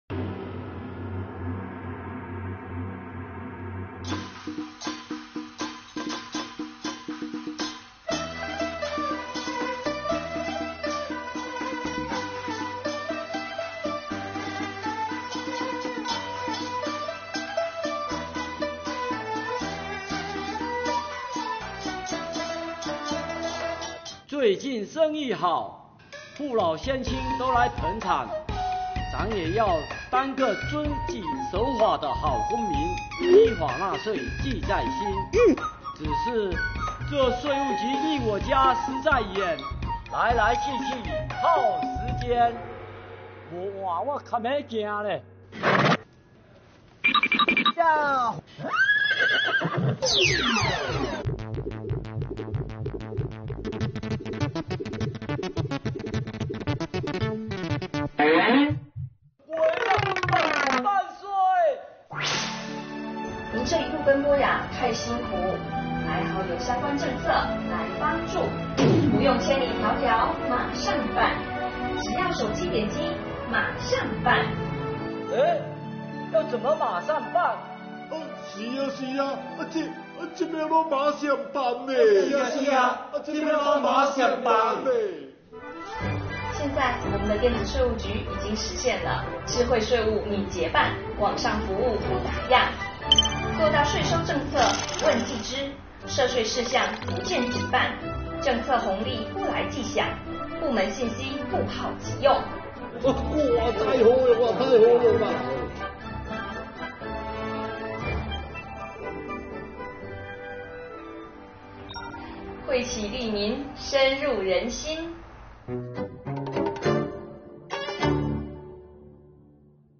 作品结合国家级非物质文化遗产——漳州布袋木偶戏，配以税务干部演绎，将税务相关的惠民便民措施和优惠政策写成台词，用木偶戏旁白唱腔表现出来，形式新颖、构思巧妙，激发读者观看兴趣。